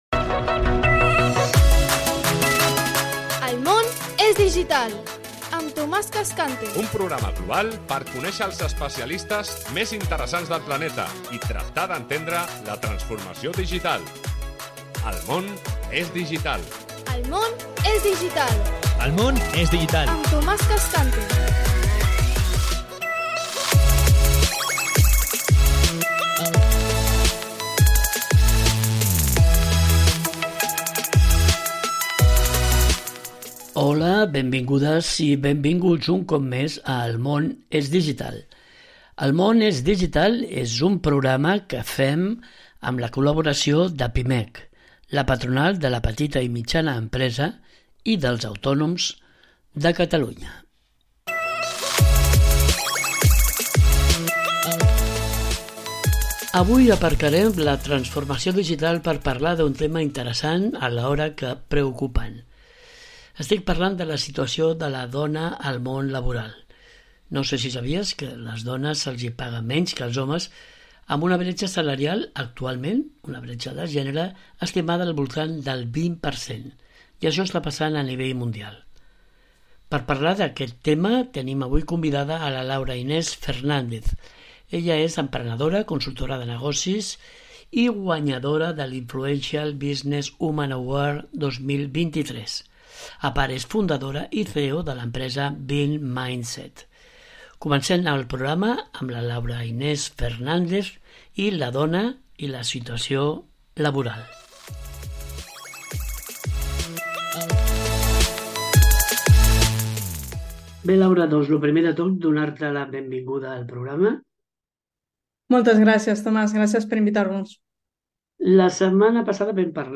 Cada setmana ens apropem a aquesta nova realitat a través de les entrevistes i les tertúlies amb destacats especialistes, directius, emprenedors i usuaris de les noves tecnologies.